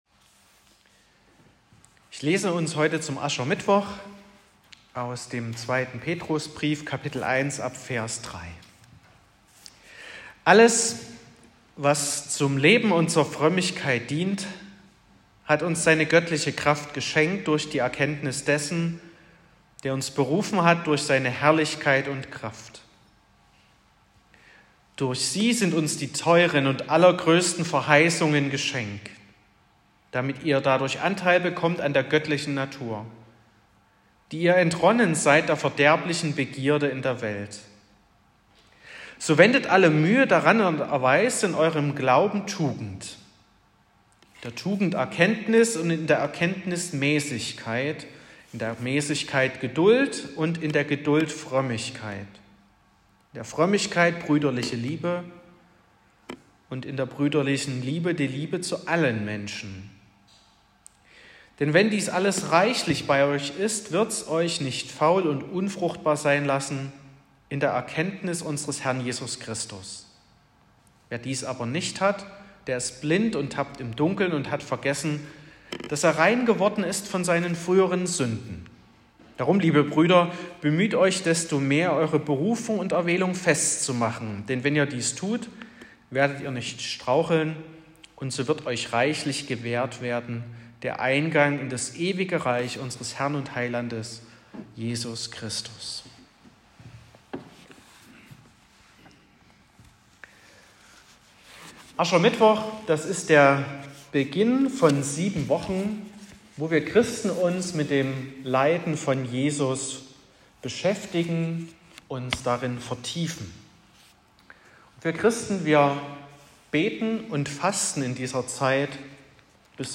14.02.2024 – gemeinsame Andacht zum Frühjahrsbußtag
Predigt (Audio): 2024-02-14_Sind_Christen_die_netteren_Menschen_.m4a (6,2 MB)